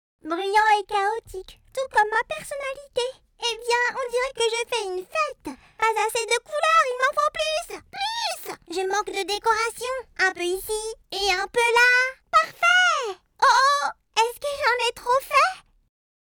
0711demo-kids_voice.mp3